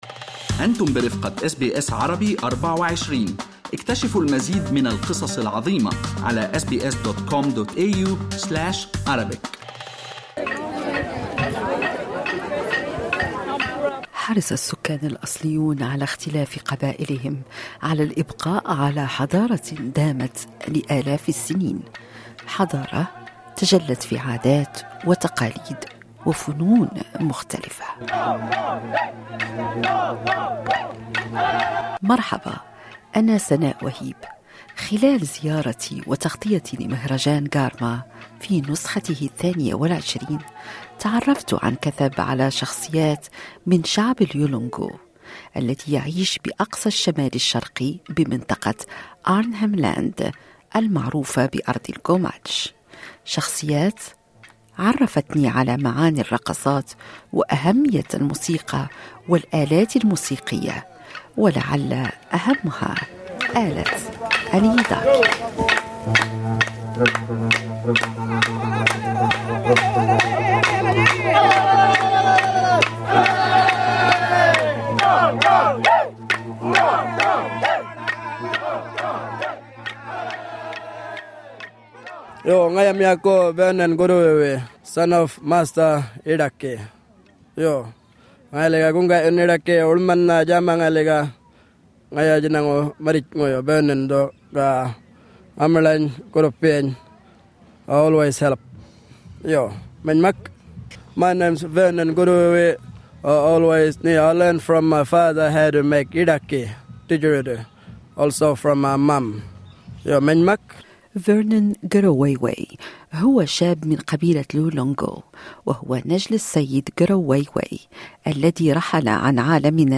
مهرجان غارما 2022 Source